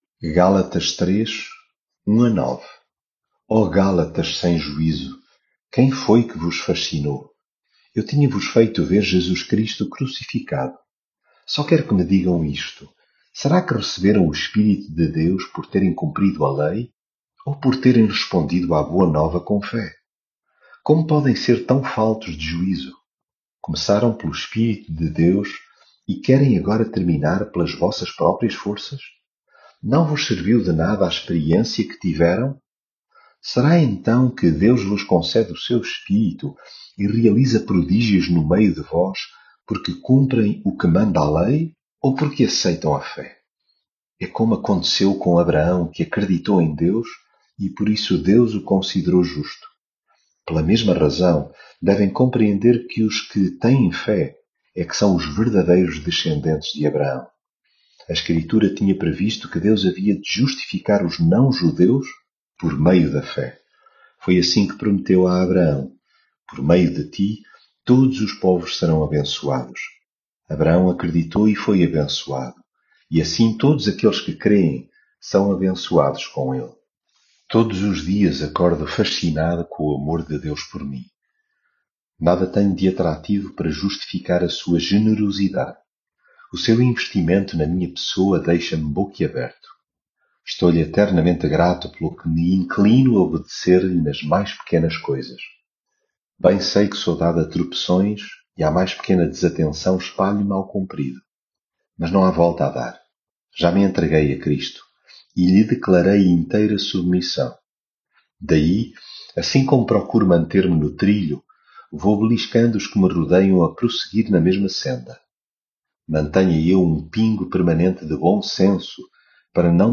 leitura bíblica